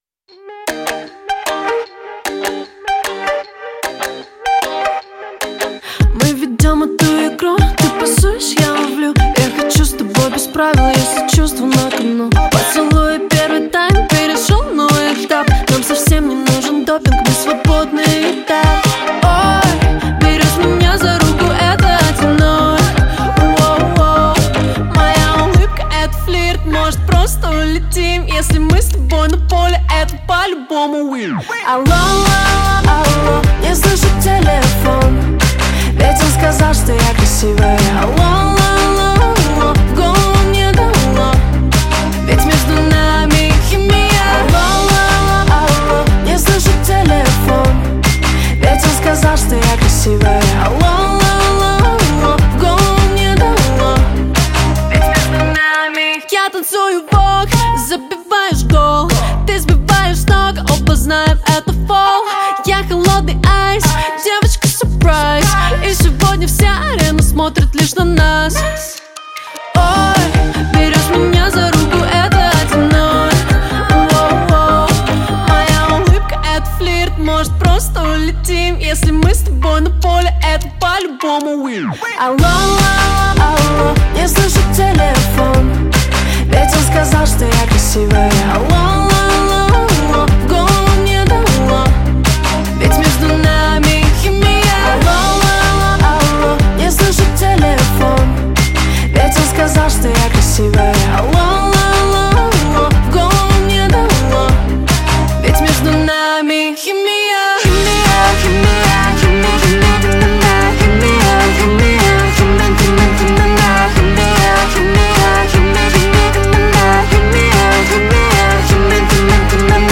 Жанр: Жанры / Поп-музыка